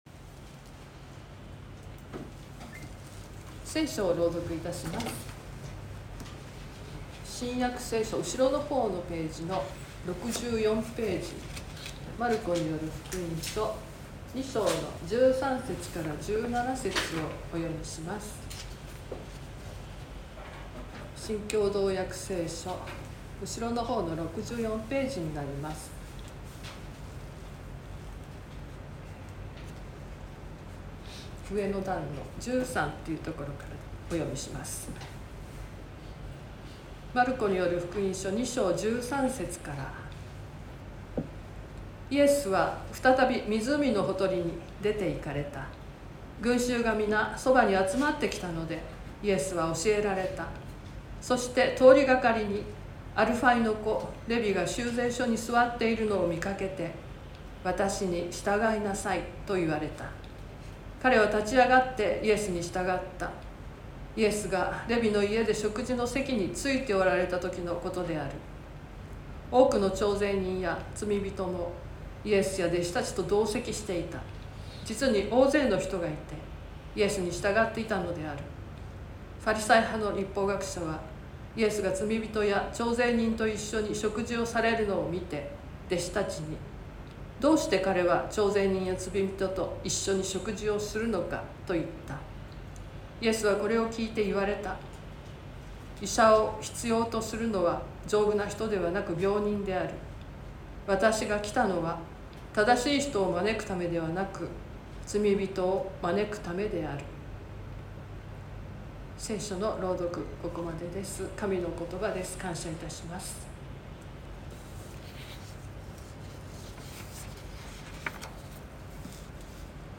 説教アーカイブ。
Youtubeで直接視聴する 音声ファイル 礼拝説教を録音した音声ファイルを公開しています。